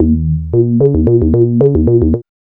3008L B-LOOP.wav